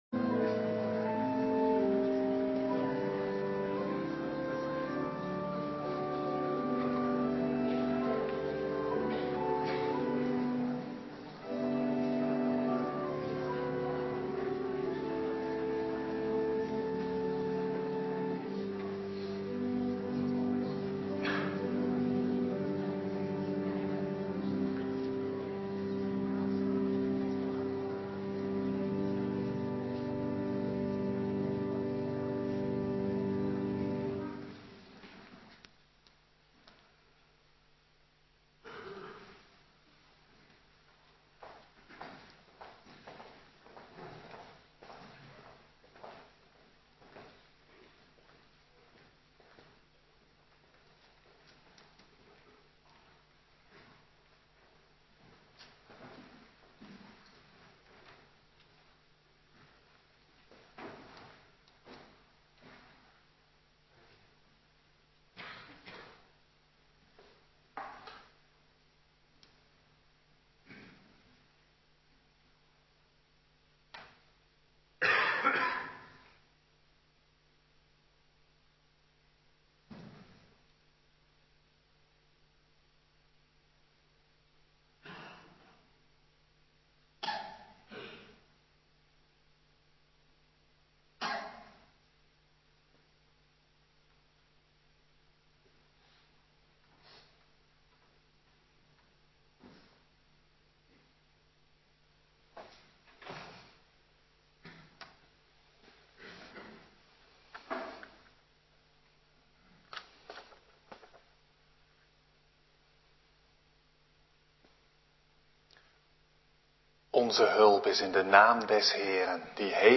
Kerkdienst naluisteren